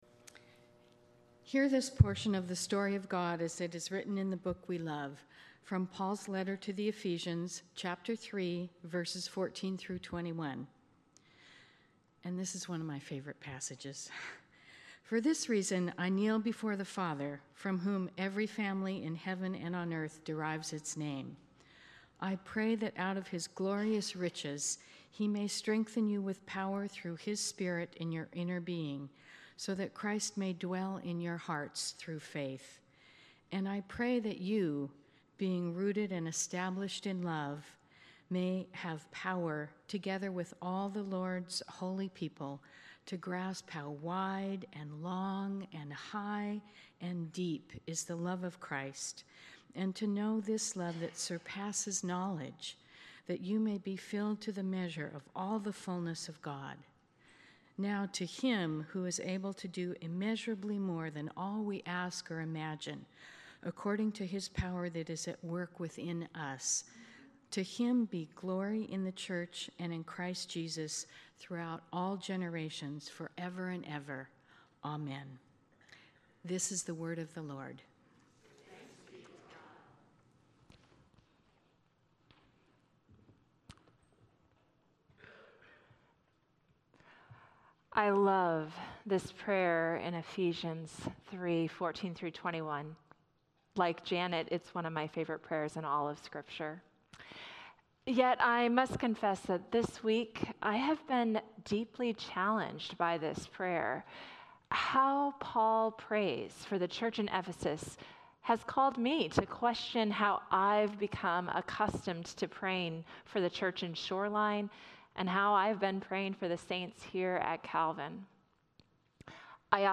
Passage: Ephesians 3:14–21 Service Type: Sunday Morning